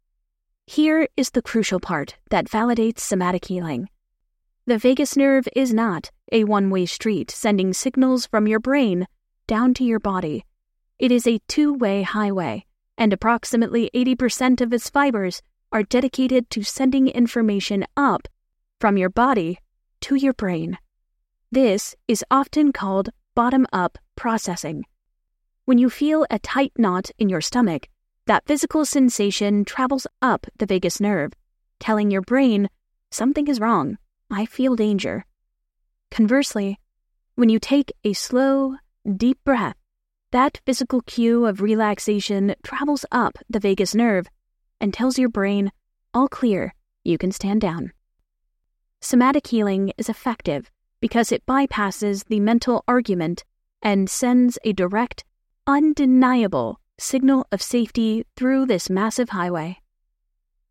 Main voice: nurturing, playful and compassionate.
Gen Amer F. Kind/Informative
0326Self_Help_-_Audiobook.mp3